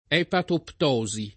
epatoptosi [ H patopt 0@ i ]